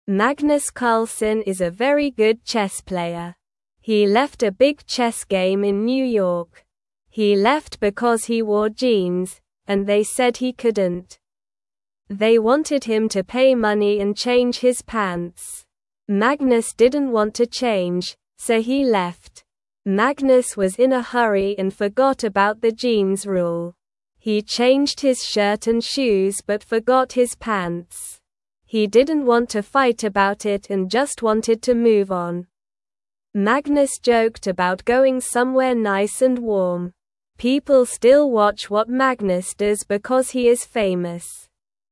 Slow
English-Newsroom-Beginner-SLOW-Reading-Magnus-Carlsen-Leaves-Chess-Game-Over-Jeans-Rule.mp3